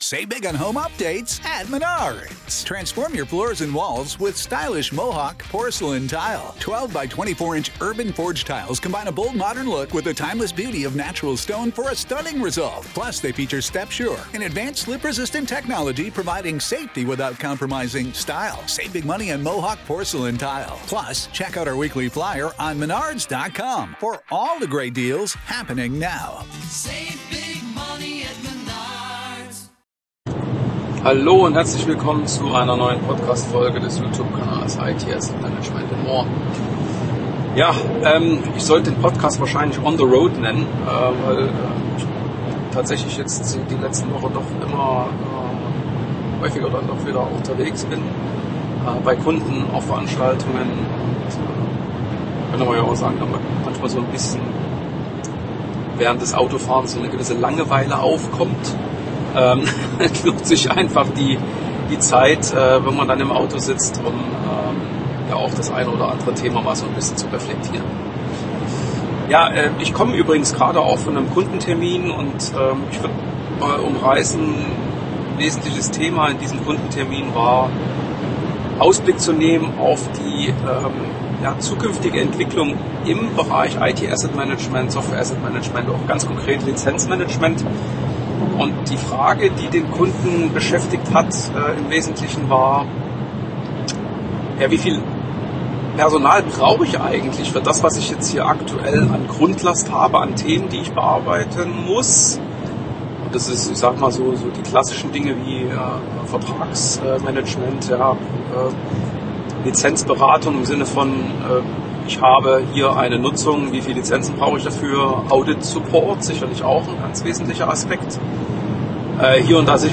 On the Road - deswegen auch mit etwas Hintergrundgeräuschen - kann man die Zeit auch dazu nutzen, die diskutierten Themen zu reflektieren. Im Mittelpunkt stand die Frage, inwiefern mit der zunehmenden Verwendung von Cloud Services der Aufwand für das Lizenzmanagement zu oder doch eher abnimmt.